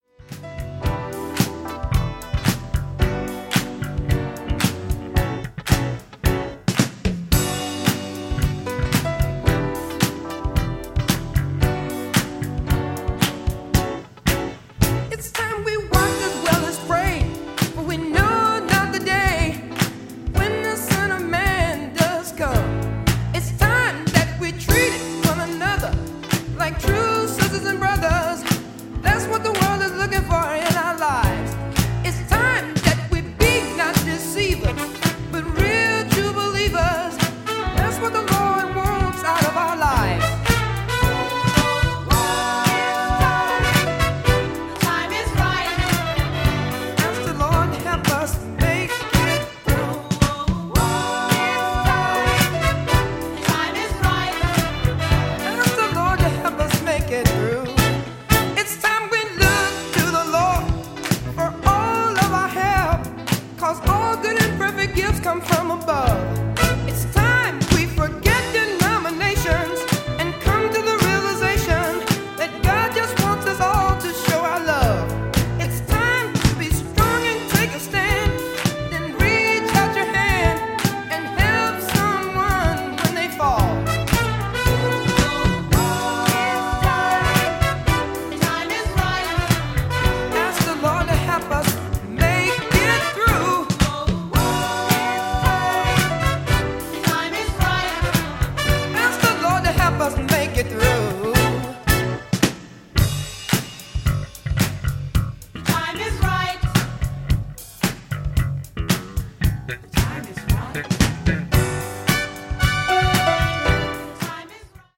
funky jams.
Funk, Gospel
Soul